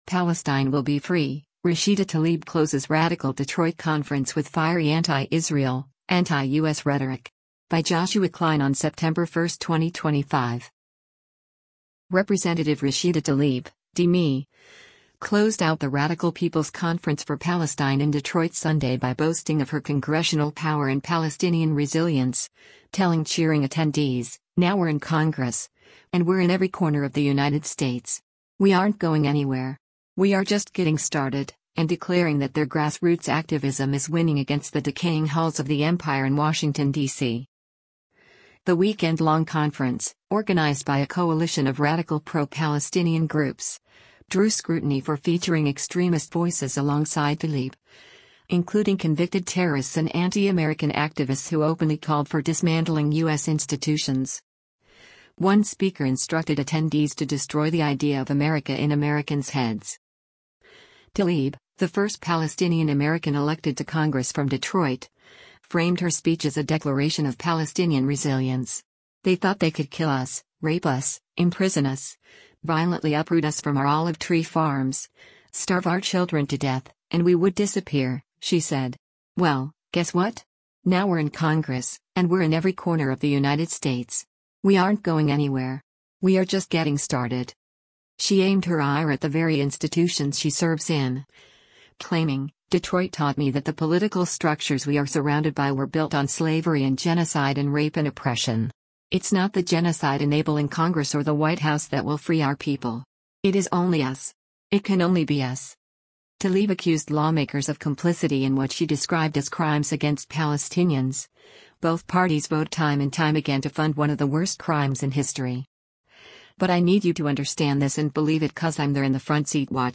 Rep. Rashida Tlaib (D-MI) closed out the radical “People’s Conference for Palestine” in Detroit Sunday by boasting of her congressional power and Palestinian resilience, telling cheering attendees, “Now we’re in Congress, and we’re in every corner of the United States. We aren’t going anywhere. We are just getting started,” and declaring that their grassroots activism is winning against the “decaying halls of the empire in Washington, DC.”
Tlaib closed by emphasizing the inevitability of Palestinian freedom, reading a poem from a Gazan mother and leading chants of “Free, free Palestine.”